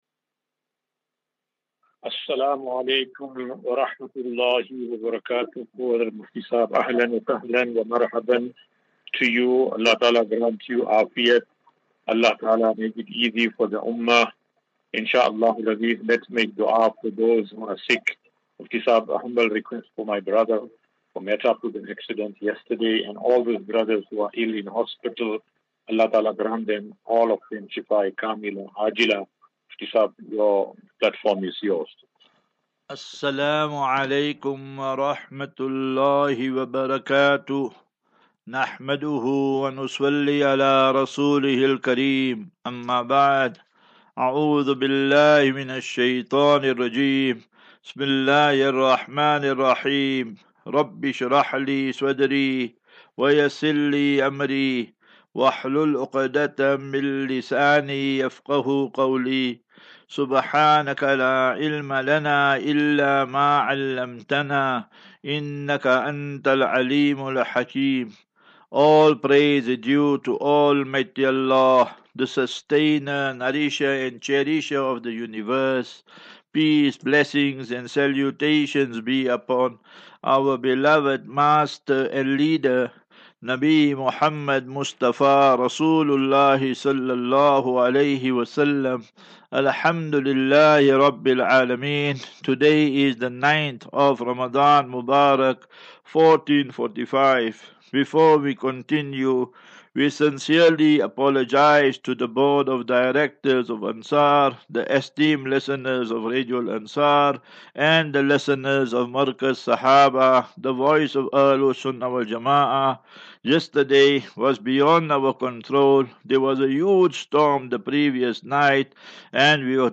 Daily Naseeha.